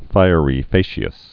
(fīə-rē fāshē-əs, fāshəs)